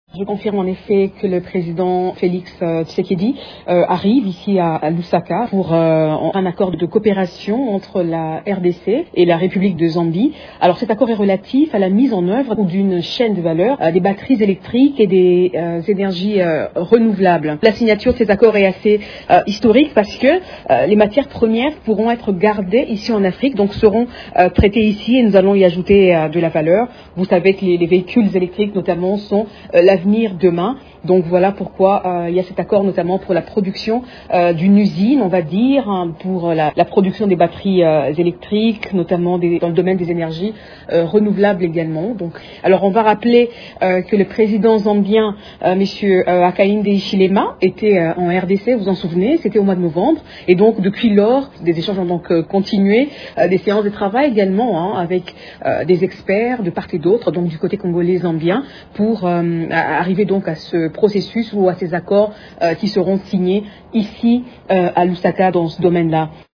La porte-parole-adjointe du chef de l’Etat, Tina Salama, a expliqué que cet accord permettra à la RDC de traiter des matières premières et de bénéficier de leurs valeurs ajoutées.
jointe au téléphone